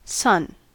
/sʌn/
sun-us.mp3